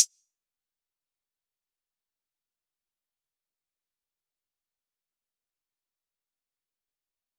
Closed Hats
Metro Hats [Chains].wav